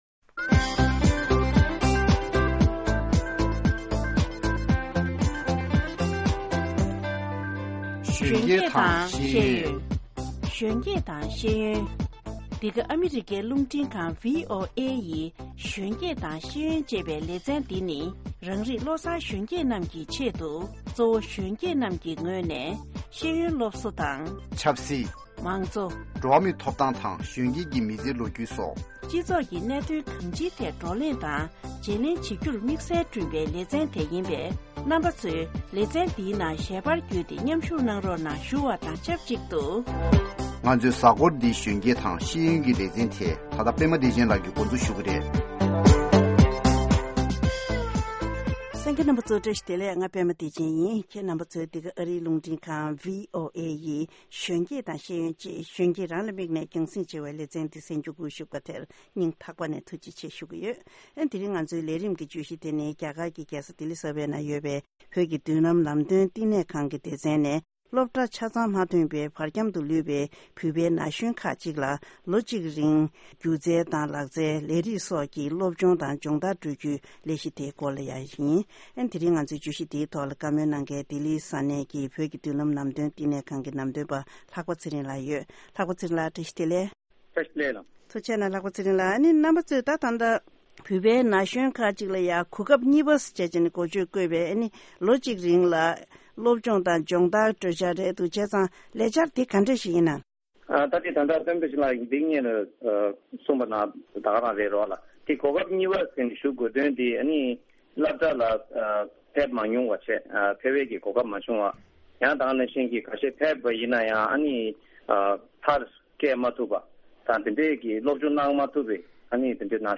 ང་ཚོའི་གཟའ་འཁོར་འདིའི་གཞོན་སྐྱེས་དང་ཤེས་ཡོན་གྱི་ལས་རིམ་ནང་དུ་འབྲེལ་ཡོད་མི་སྣ་ཚོར་བགྲོ་གླེང་གནང་བ་ཞིག་གསན་རོགས་གནང་།